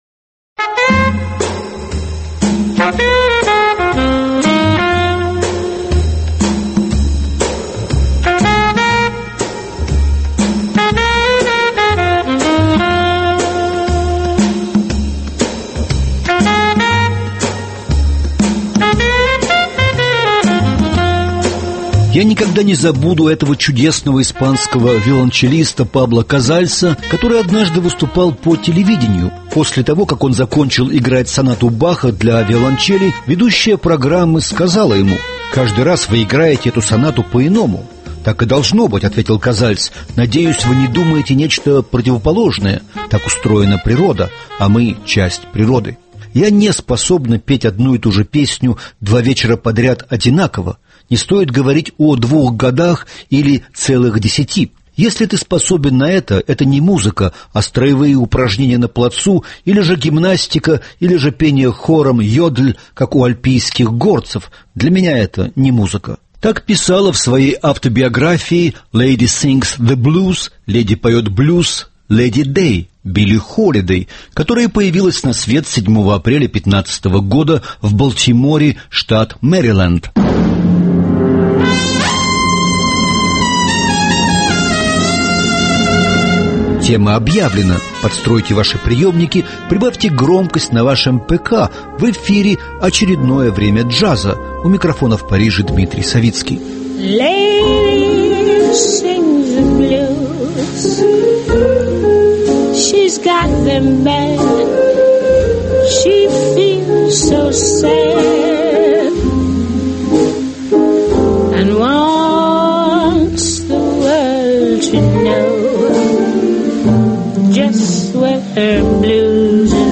Рассказывает Дмитрий Савицкий.
Легендарная блюзовая певица